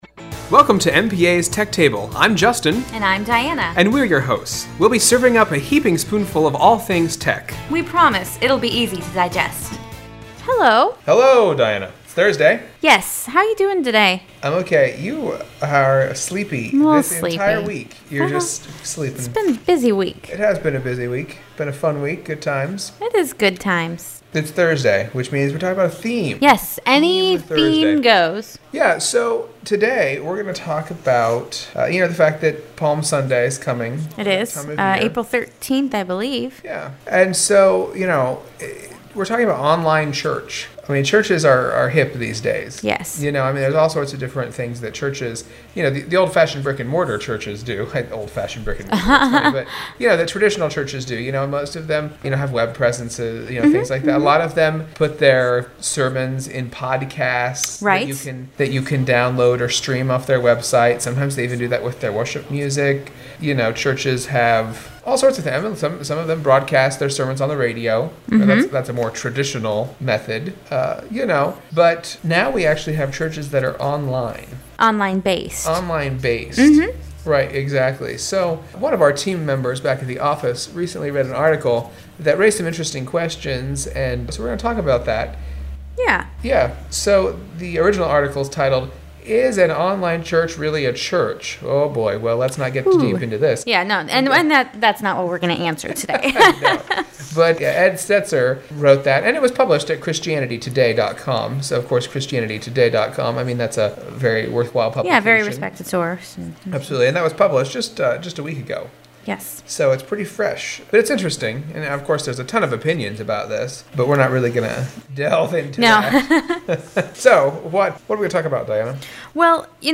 Tech Table Radio Show